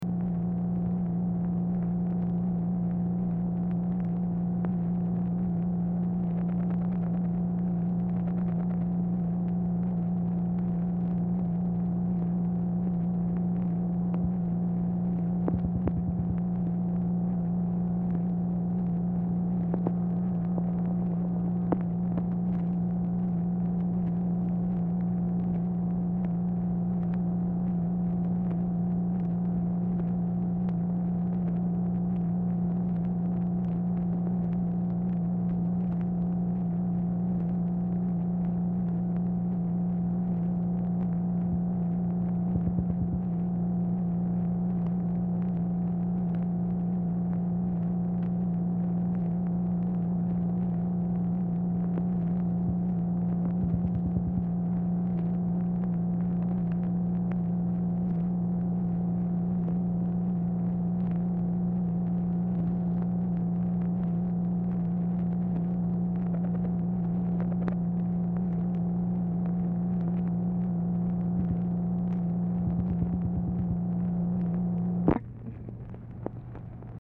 Telephone conversation # 9433, sound recording, MACHINE NOISE, 1/6/1966, time unknown | Discover LBJ
Telephone conversation
Dictation belt
Mansion, White House, Washington, DC